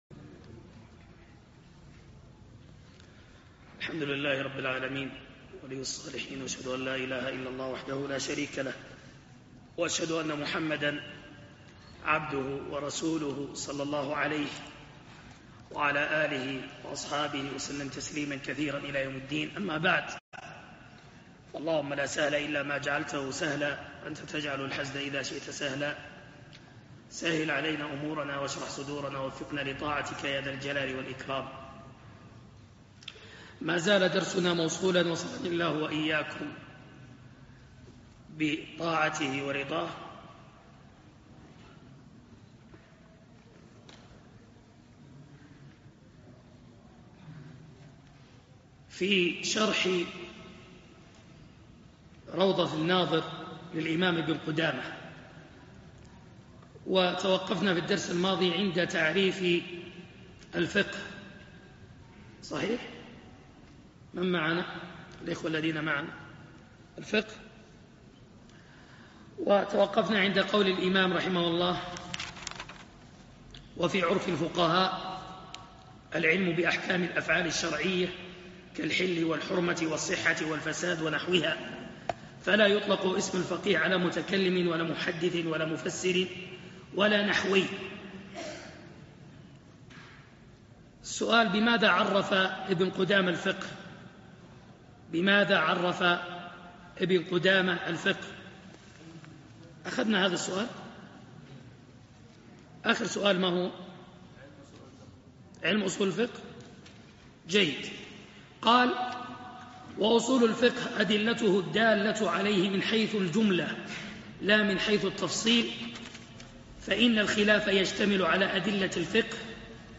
دروس وسلاسل